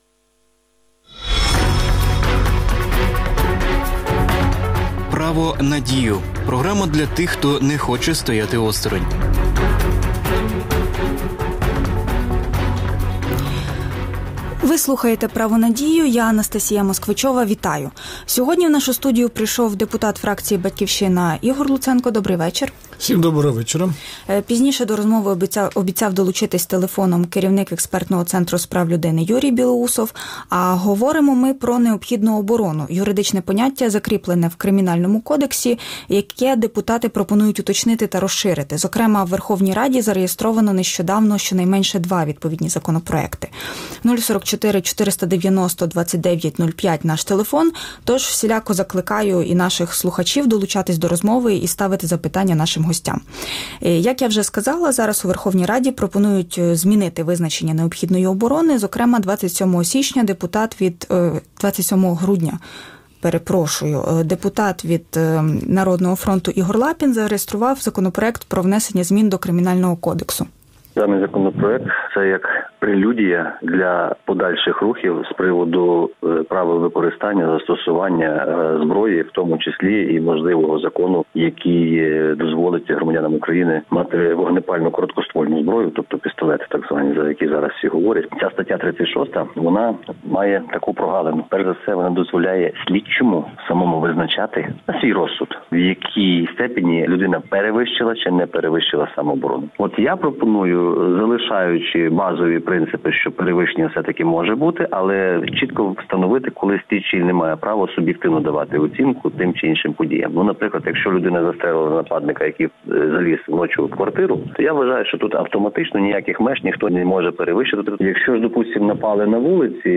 Гості: депутат фракції «Батьківщина» Ігор Луценко
(телефоном)